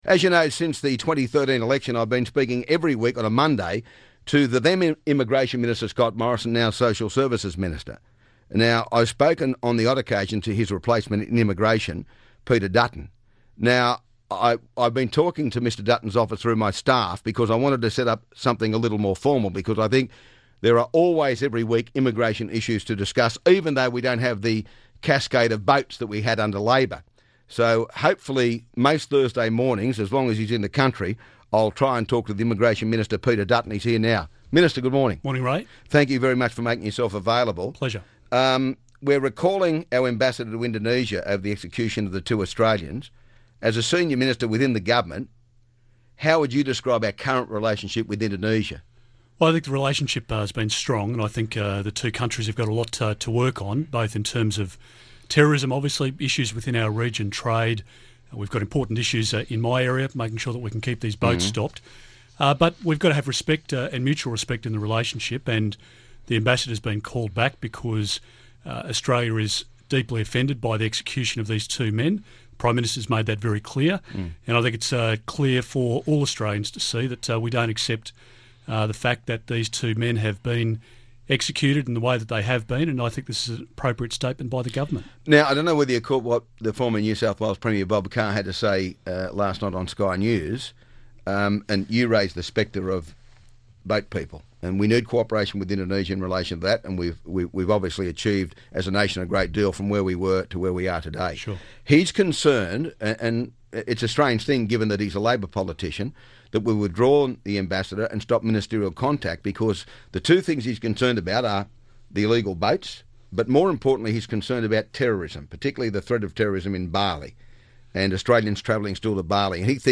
Immigration Minister Peter Dutton joins Ray in the studio to talk about the fallout from the Bali Nine executions, an Australian doctor who has joined Islamic State, illegal workers and a bridging visa for a mother with an autistic son